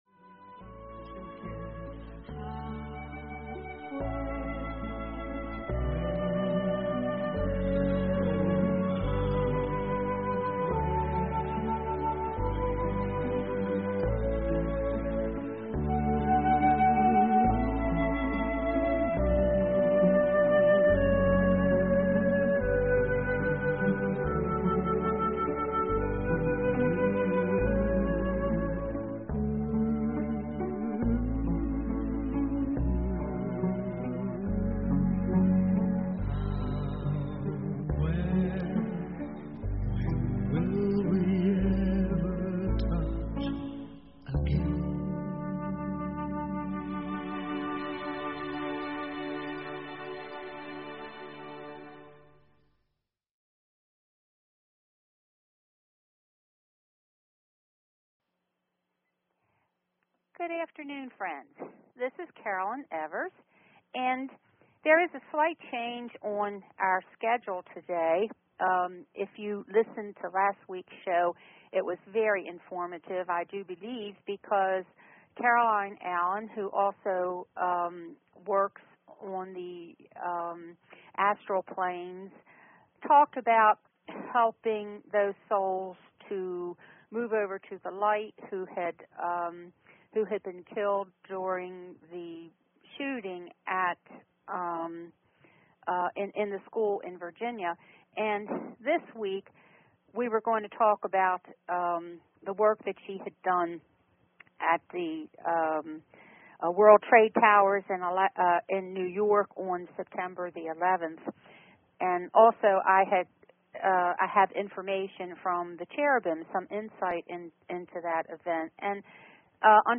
Talk Show Episode, Audio Podcast, The_Message and Courtesy of BBS Radio on , show guests , about , categorized as
Show Headline The_Message Show Sub Headline Courtesy of BBS Radio How are the shape of the cells of the etheric body changing to prepare us for ascension? A caller called in to clear the physical of curses and spells.